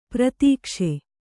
♪ pratīkṣe